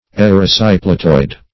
Search Result for " erysipelatoid" : The Collaborative International Dictionary of English v.0.48: Erysipelatoid \Er`y*si*pel"a*toid\ ([e^]r`[i^]*s[i^]*p[e^]l"[.a]*toid), a. [Gr.